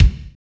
Index of /90_sSampleCDs/Northstar - Drumscapes Roland/DRM_R&B Groove/KIK_R&B Kicks x
KIK R B K304.wav